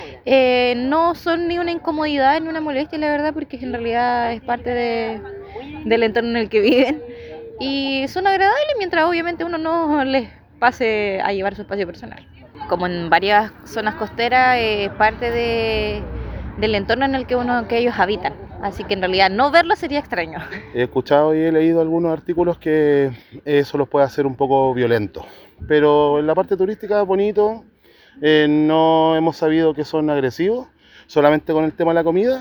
En contraste, muchos turistas aseguran no percibir un riesgo y consideran a los lobos marinos como parte del atractivo turístico de la ciudad, destacando su presencia como una experiencia positiva durante su visita.
cuna-3-mix-turistas.mp3